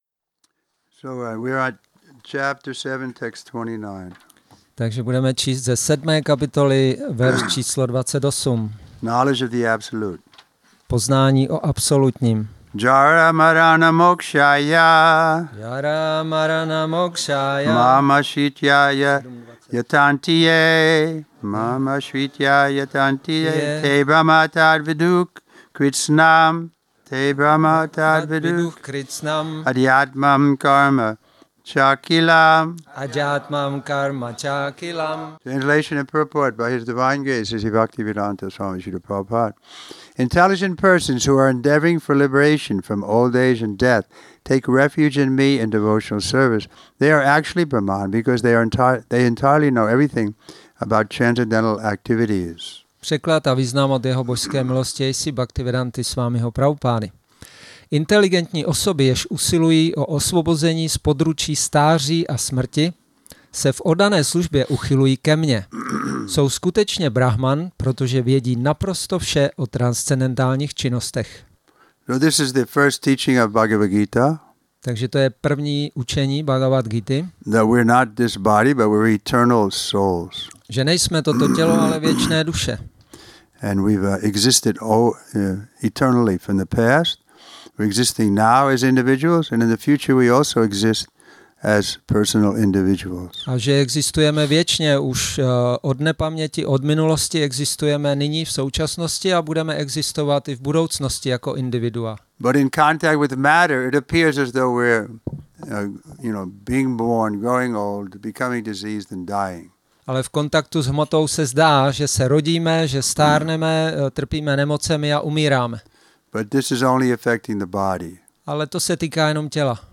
Šrí Šrí Nitái Navadvípačandra mandir
Přednáška BG-7.28